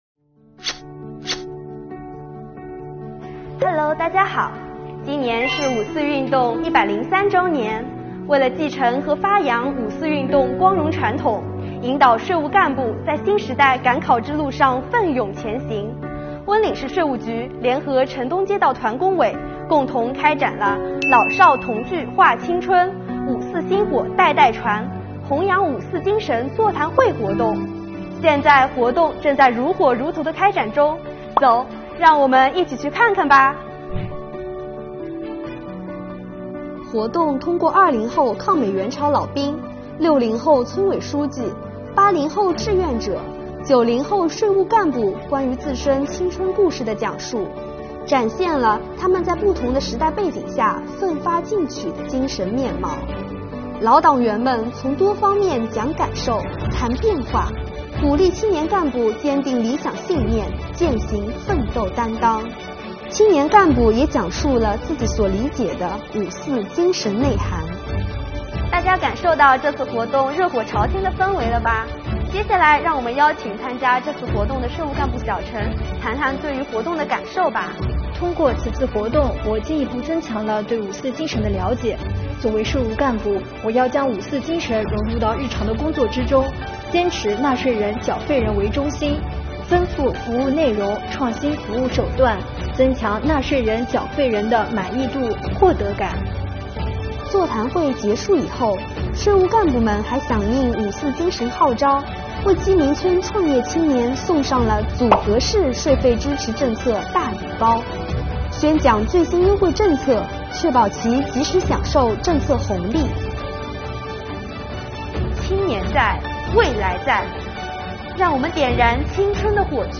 在浙江，为引导青年干部在新时代赶考路上奋勇前行，国家税务总局温岭市税务局走进城东街道鸡鸣村，开展了“老少同聚话青春 五四薪火代代传——弘扬五四精神座谈会”活动。